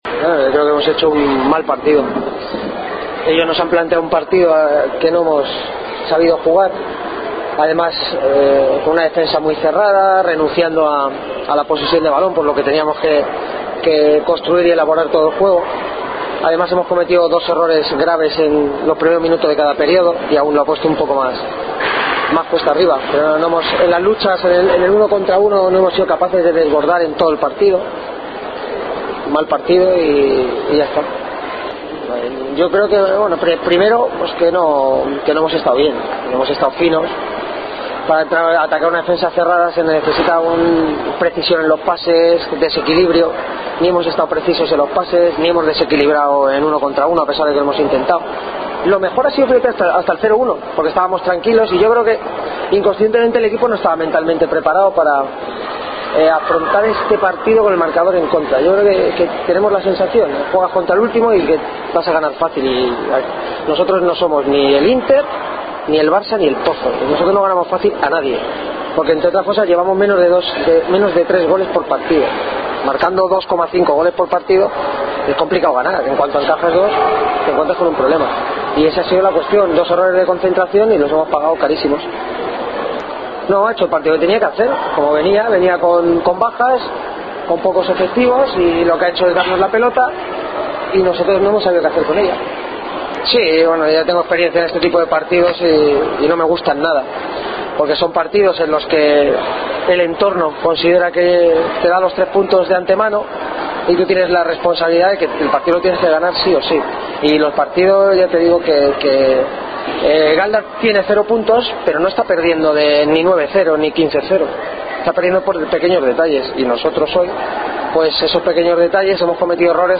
Rueda de prensa Burela-Gran Canaria